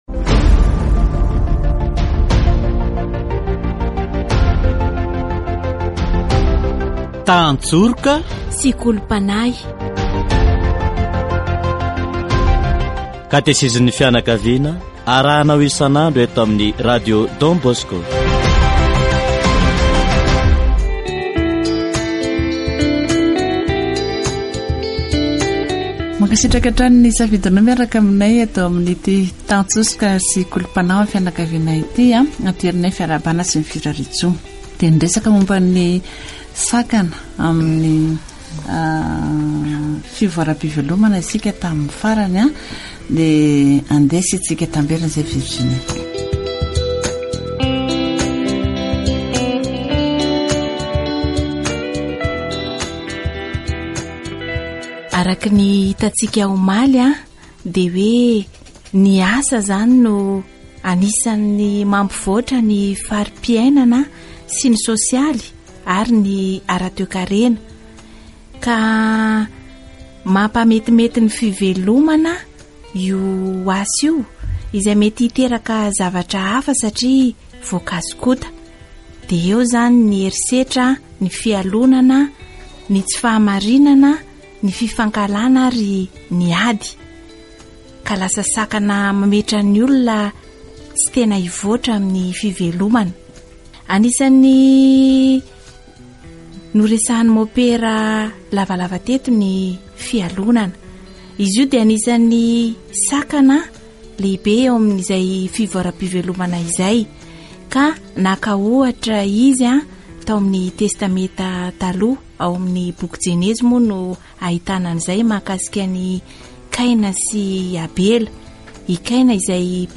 Catégorie : Approfondissement de la foi